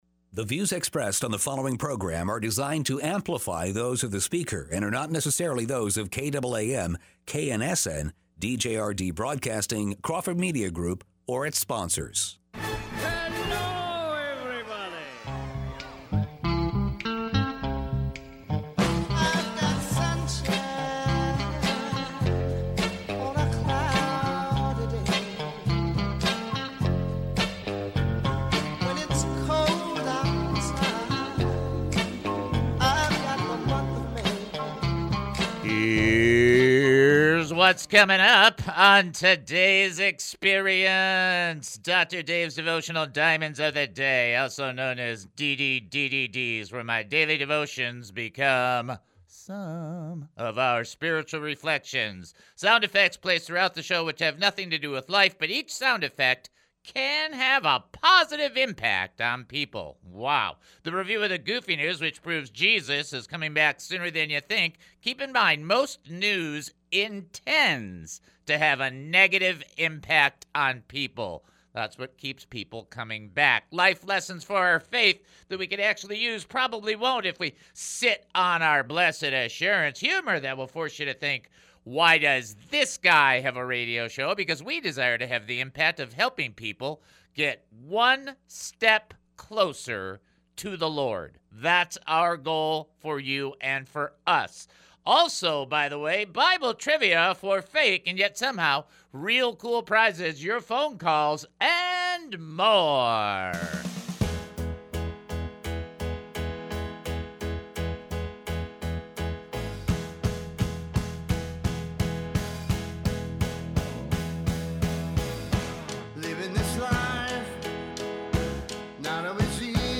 calls in with another great question. Who named Adam?